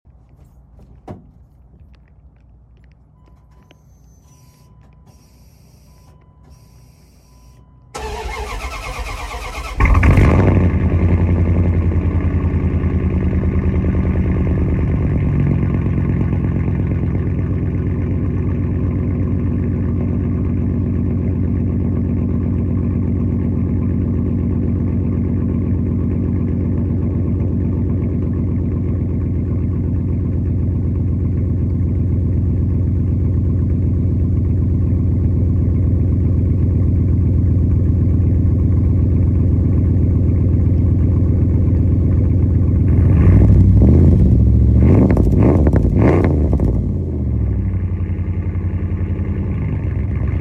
01 ram 5.9 magnum. three sound effects free download
01 ram 5.9 magnum. three month cold start